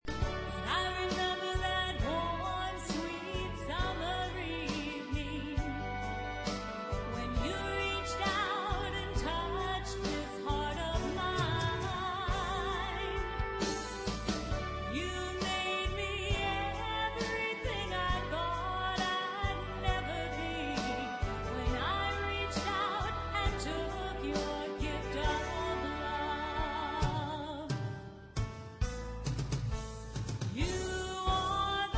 Contemporary Christian